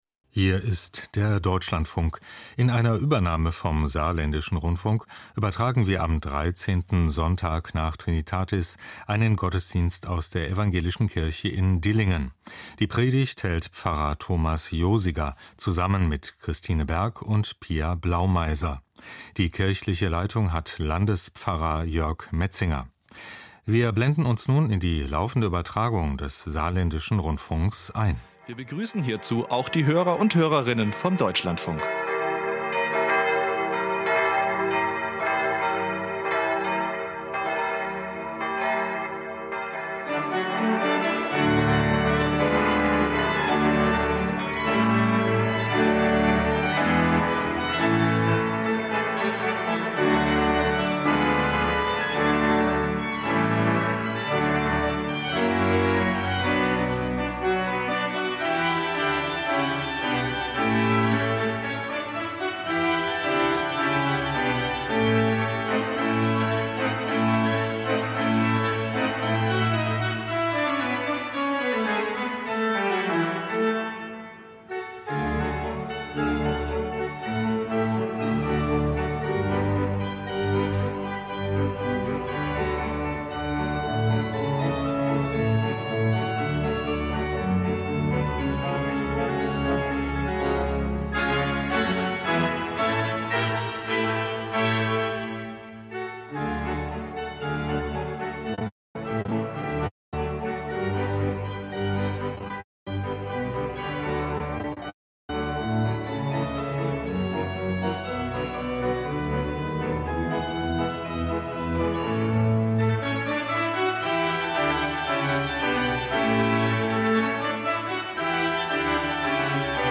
Ev. Gottesdienst aus Dillingen